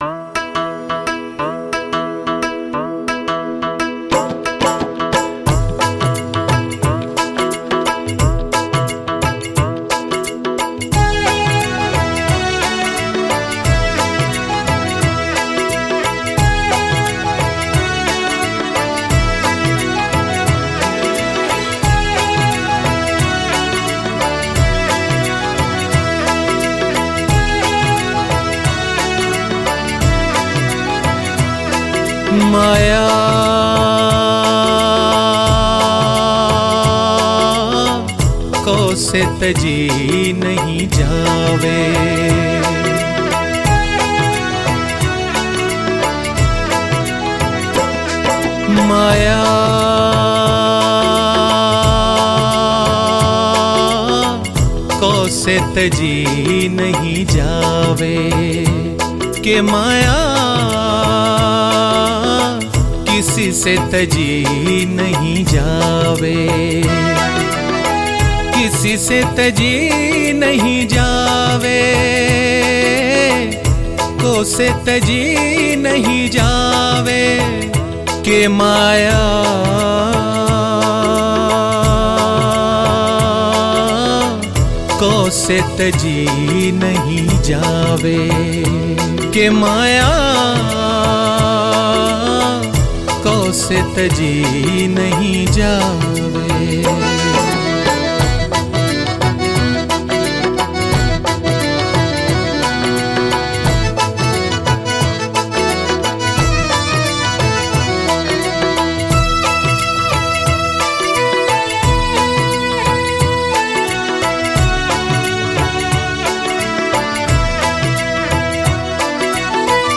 🎵 Māyā Kou Se Tajī Nahi Jāve / માયા કોઉસે તજી નહિ જાવે – રાગ : જિંજોટિ